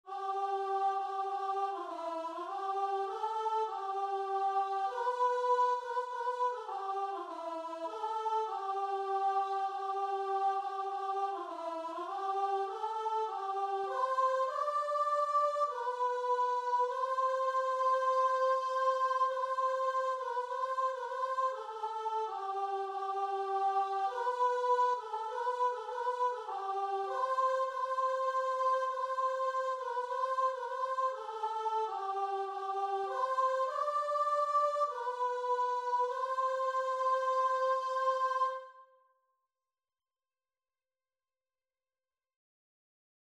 Christian
4/4 (View more 4/4 Music)
Classical (View more Classical Guitar and Vocal Music)